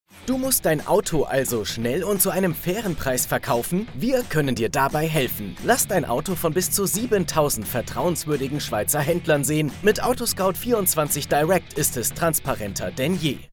Professioneller Sprecher & Moderator
Mein hauseigenes Studio in Broadcast-Qualität sorgt für exzellente Ergebnisse bei vielfältigen Projekten.
1 | Werbung